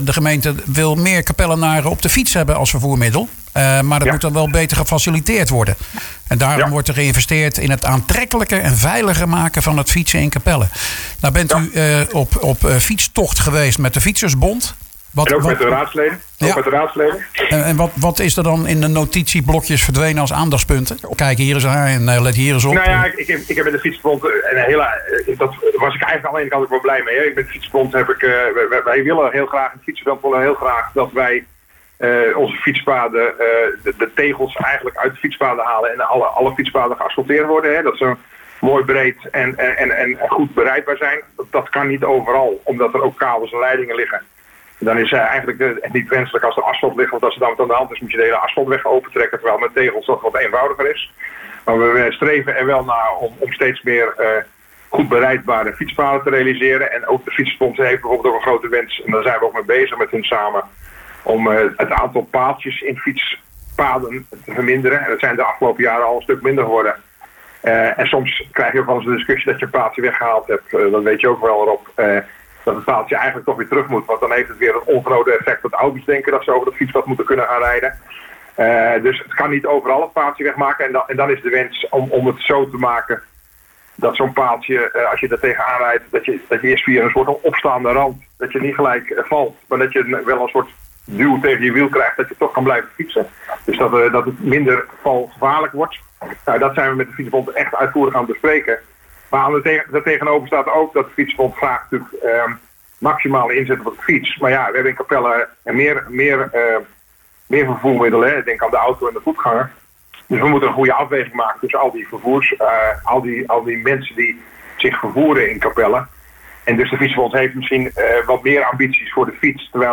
in gesprek met wethouder Rik van Woudenberg over wat al die gesprekken hebben opgeleverd.